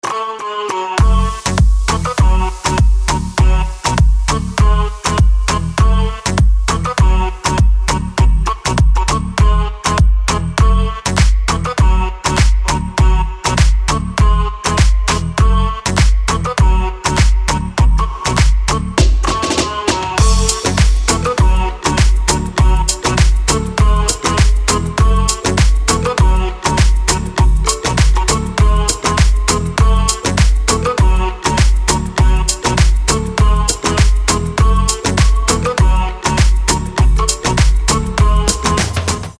• Качество: 320, Stereo
поп
dance
Electronic
без слов